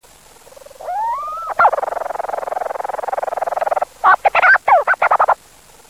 głosy innych nawałników
Nawałnik duży - hydrobates leucorhous|leach's storm-petrelmożna go obserwować w Polsce podczas sezonowych wędrówekmp346 kb